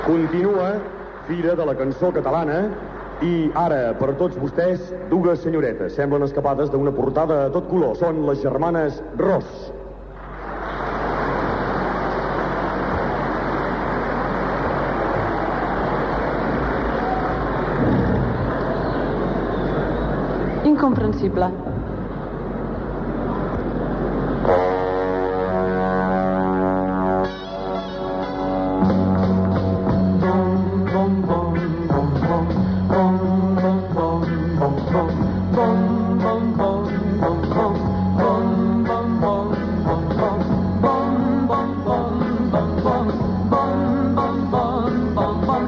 Salvador Escamilla dona pas a les cantants "Germanes Ros" a la Fira de la cançó catalana
Musical